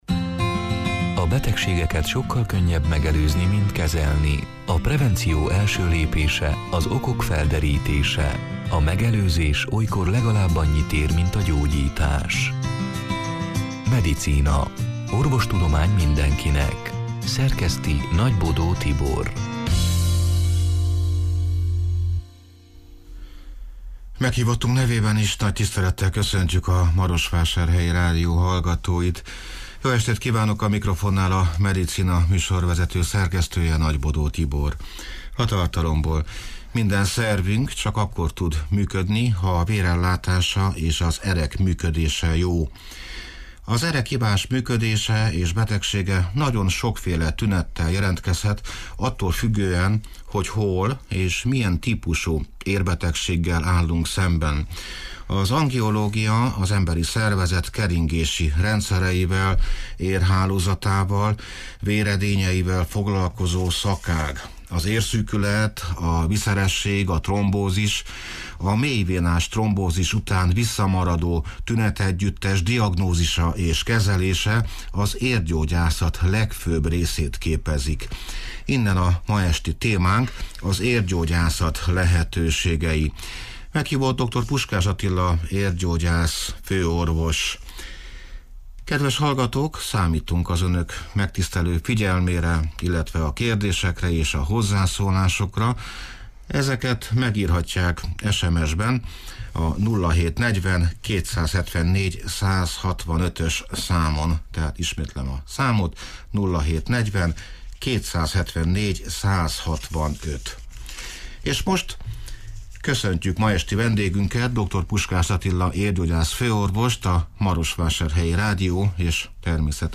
A Marosvásárhelyi Rádió Medicina (elhangzott: 2022. szeptember 14-én, szerdán este nyolc órától élőben) c. műsorának hanganyaga: Minden szervünk csak akkor tud működni, ha a vérellátása, és az erek működése jó.